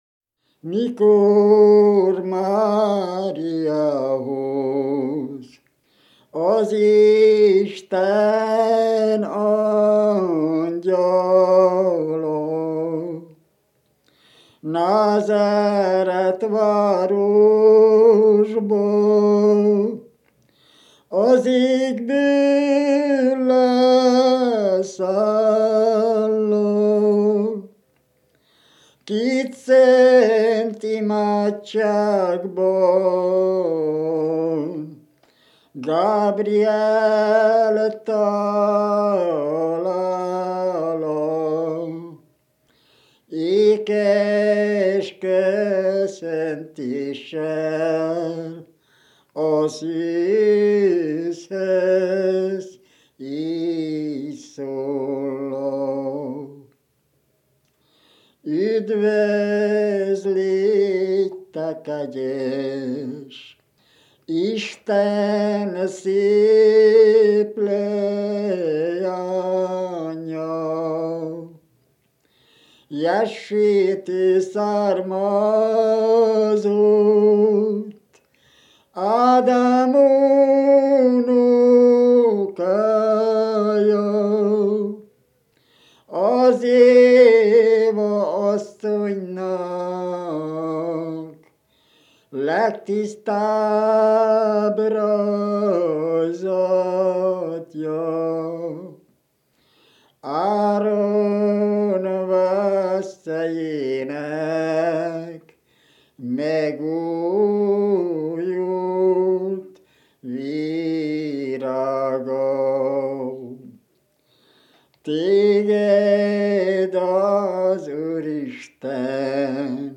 Letölthető a ZTI Publikált népzenei felvételek adatbázisából
ének
Bukovina (Moldva és Bukovina)